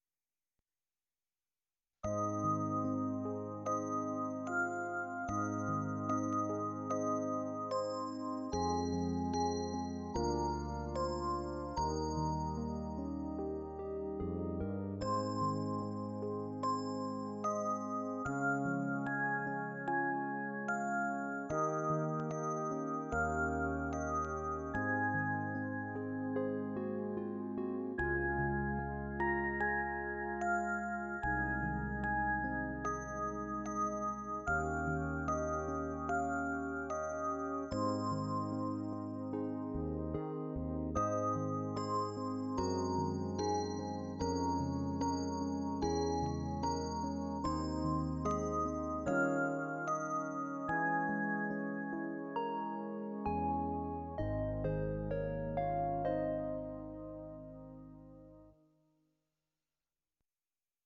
定時放送 メロディチャイム「夕焼け小焼け」